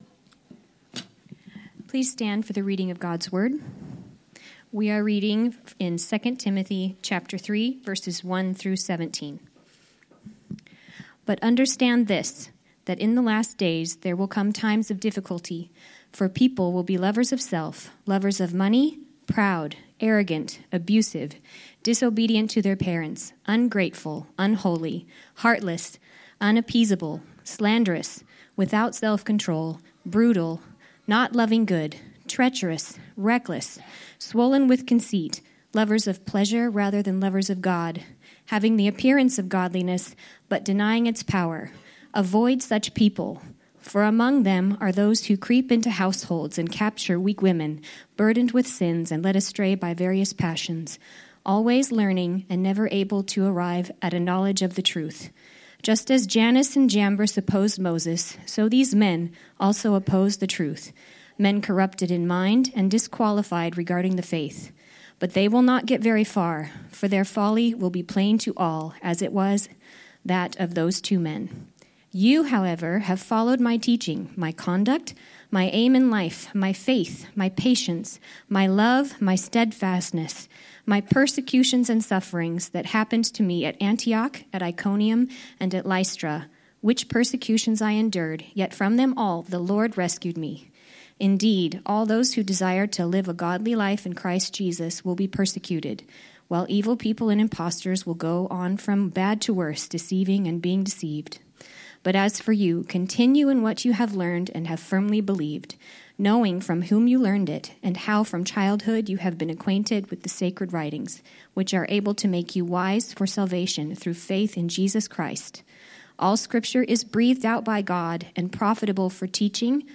Sermons - Grace Church - Pasco